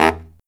LOHITSAX16-R.wav